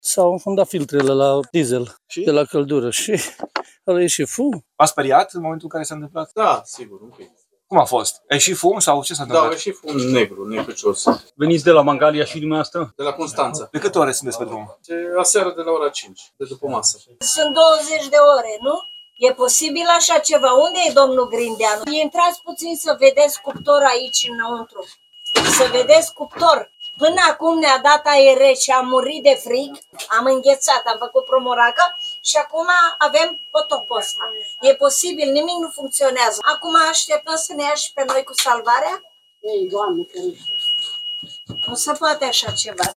Mulți dintre ei aveau peste 20 de ore de când erau pe drum. Erau supărați și foarte obosiți.
29iun-18-Coresp-AR-–vox-calatori-tren-Mangalia-Constanta.mp3